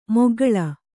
♪ moggaḷa